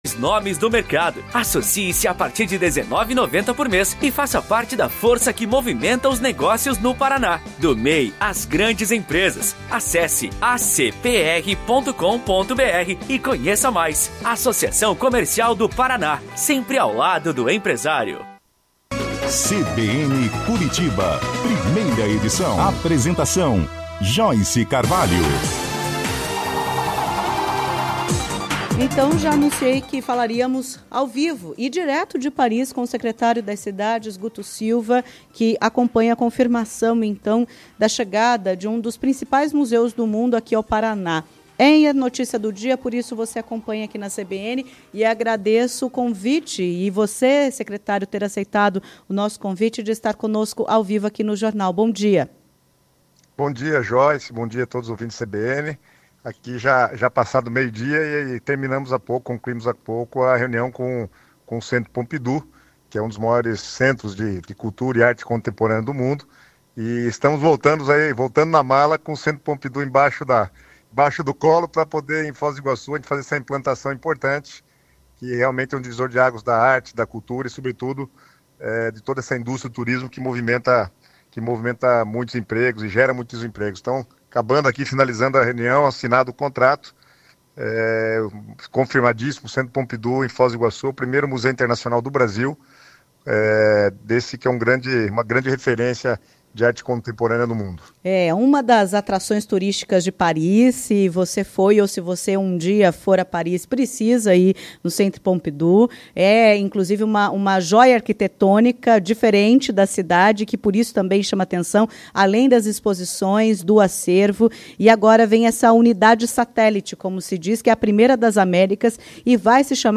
Em entrevista à CBN Curitiba, o secretário das Cidades, Guto Silva – que participou do encontro -, destacou a importância da unidade em Foz do Iguaçu, a primeira nas Américas.